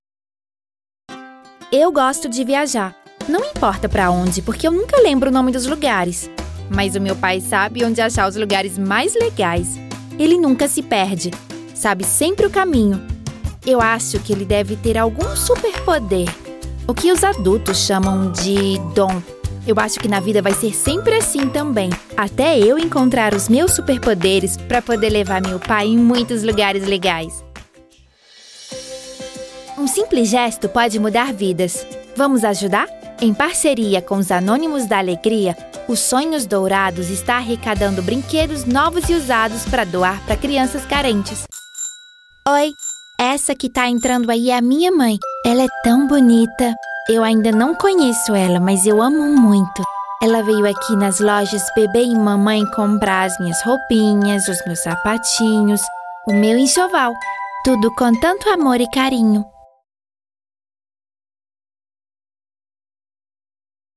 Her voice is described as versatile, friendly, conversational and her voice range goes from 12 to 35 years old.
Sprechprobe: Sonstiges (Muttersprache):